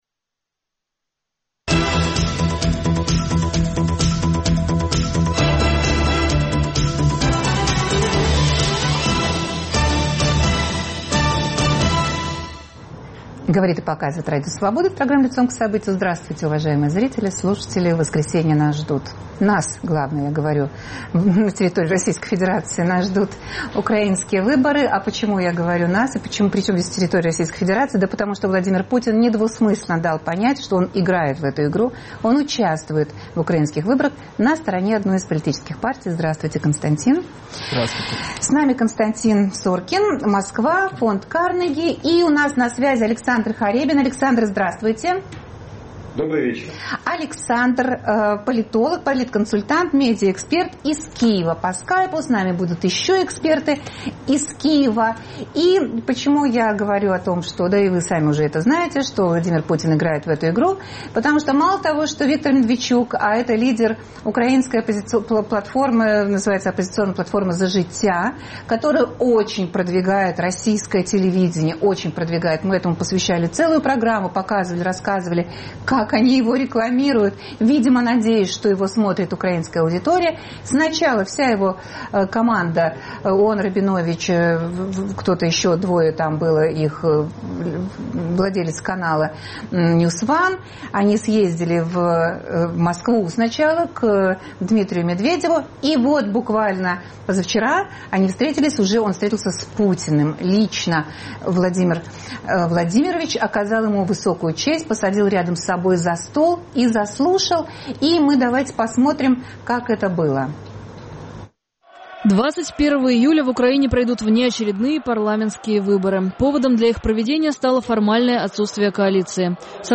Вопрос: добавит ли это Медведчуку голосов украинских избирателей? В обсуждении участвуют